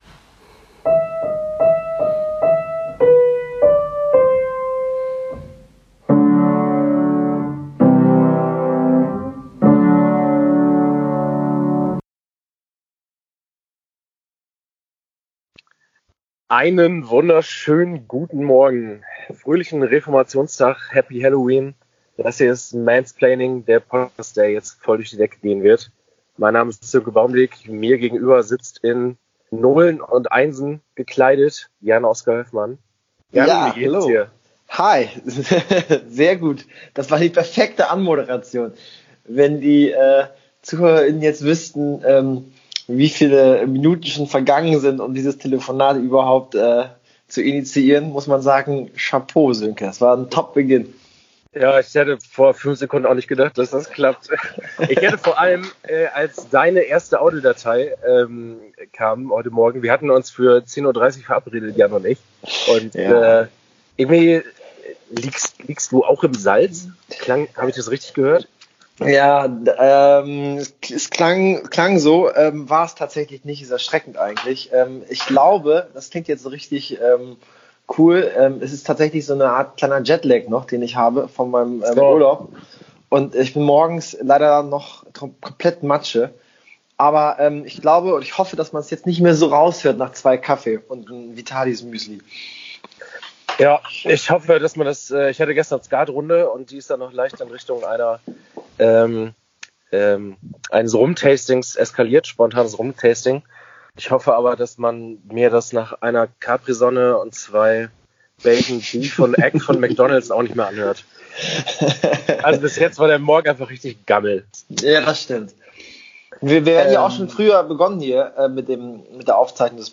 Beschreibung vor 6 Jahren +++ACHTUNG: Gute Tonqualität erst ab Folge 2!+++ Wie produziert man eine erste Podcast-Folge, ohne das ‘ne klassische erste Folge dabei herauskommt? Vorweg: Das komische Piano-Intro erklären wir erst beim nächsten Mal.